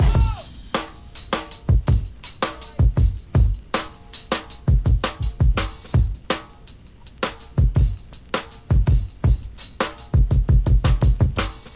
IGOR_S THEME Drum Break (81.6bpm).wav